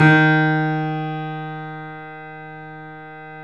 Index of /90_sSampleCDs/E-MU Producer Series Vol. 5 – 3-D Audio Collection/3D Pianos/YamaMediumVF04